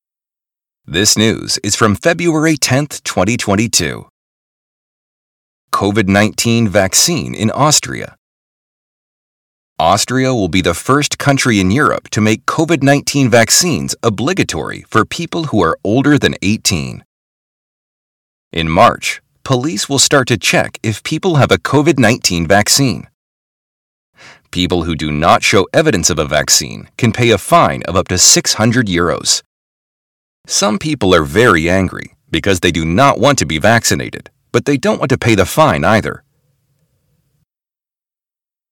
Lesson 26 - Shadowing
native speakers